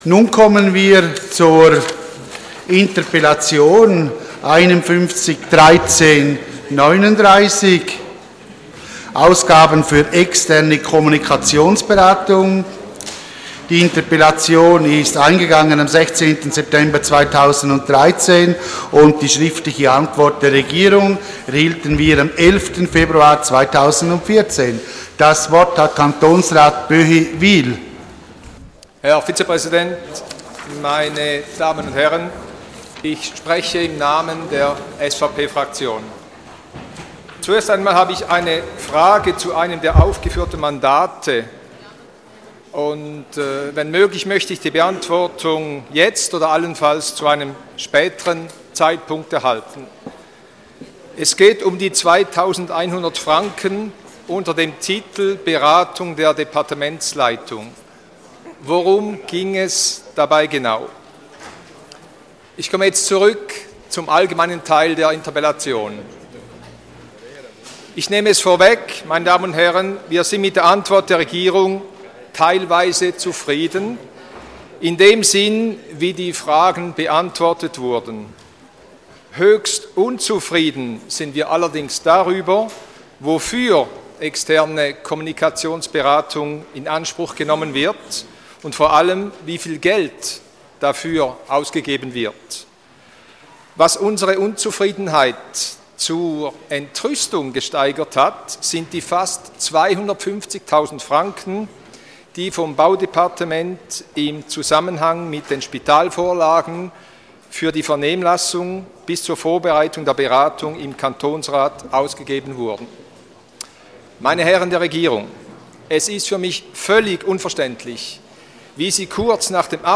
24.2.2014Wortmeldung
Session des Kantonsrates vom 24. und 25. Februar 2014